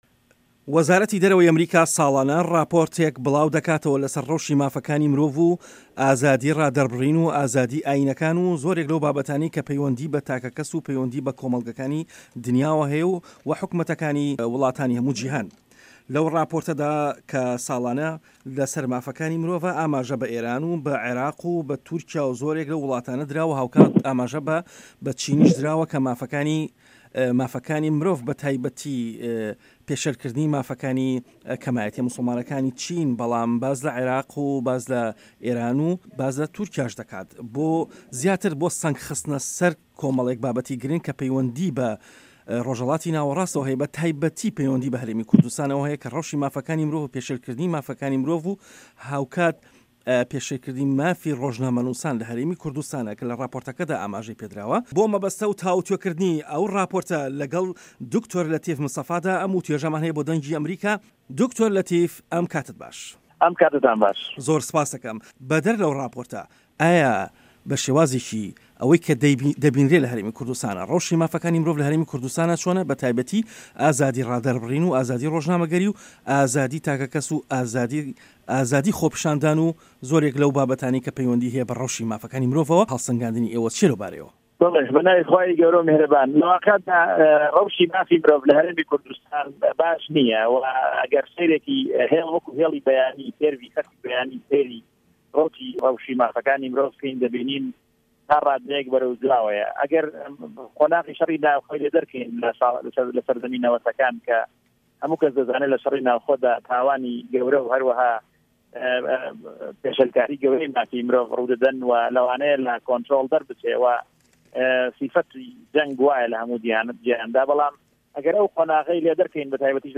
وتووێژ لەگەڵ دکتۆر لەتیف مستەفا